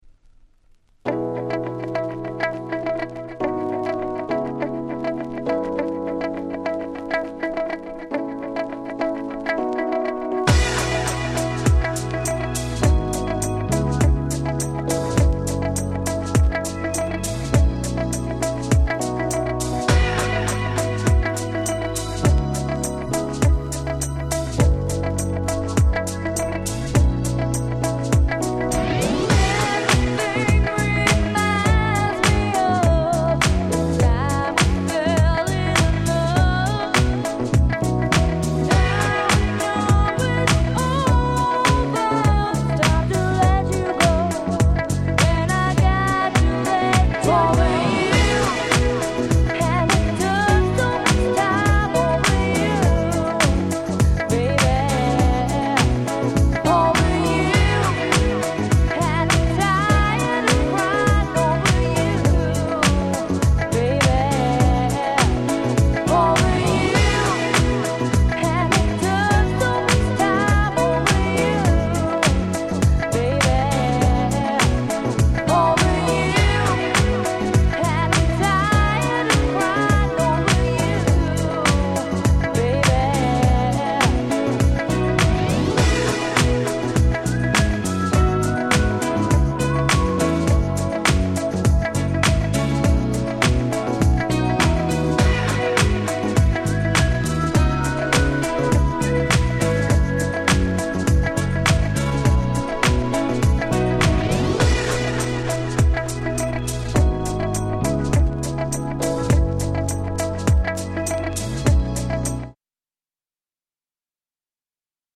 人気キャッチーR&Bコンピレーション！！